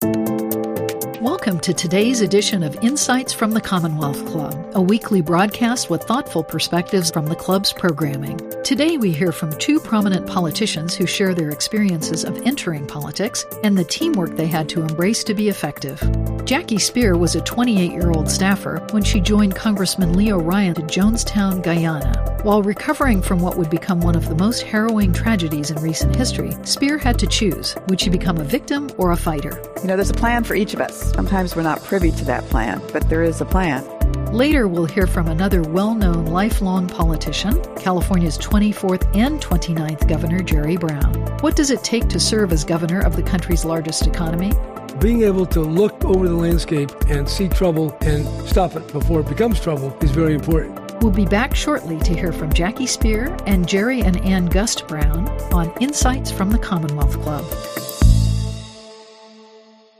Ep002 Segment A. 1-min Billboard